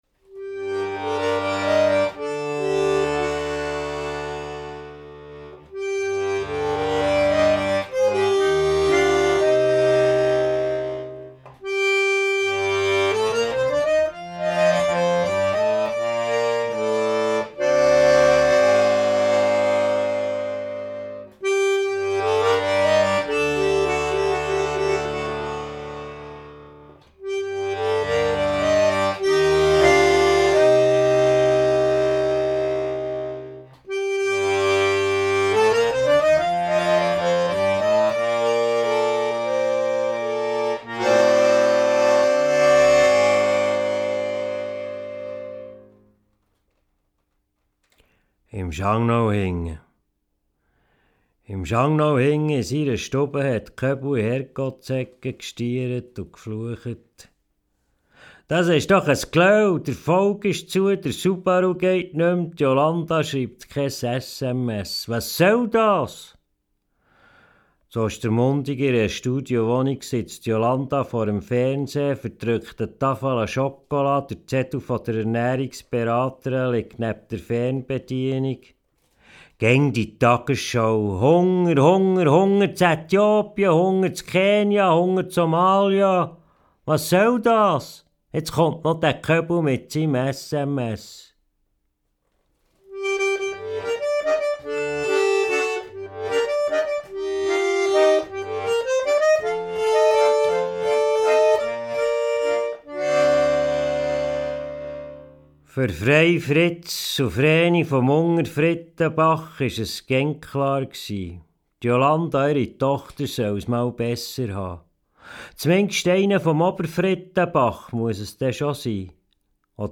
Hackbrett
gemixt mit Jazz, Tango und experimentellen Elementen